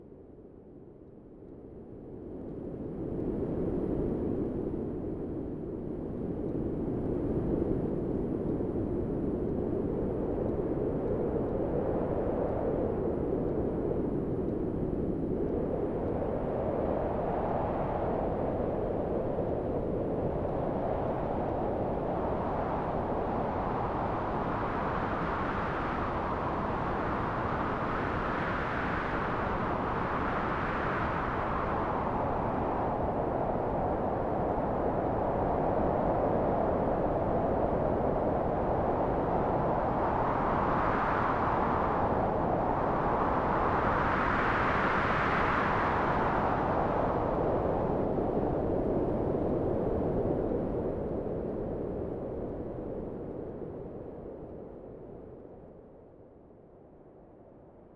loop drum experiment 149bpm